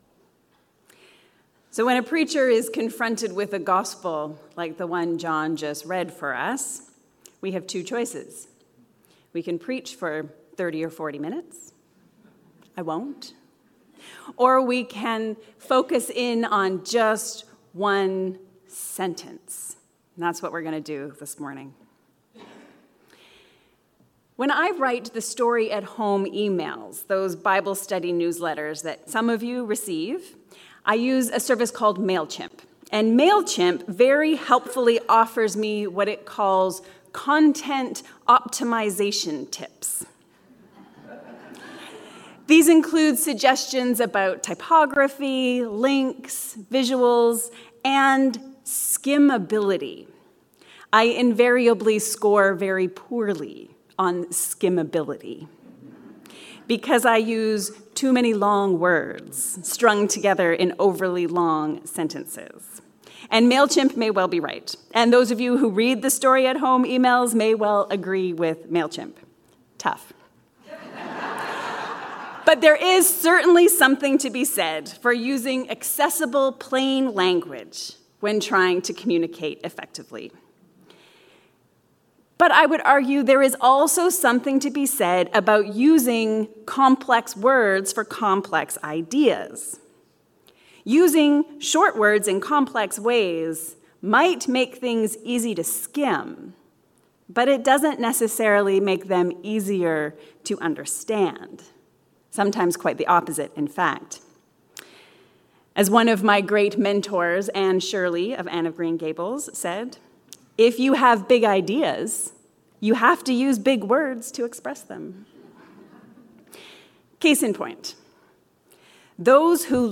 Dying to Live. A Sermon for the Fifth Sunday in Lent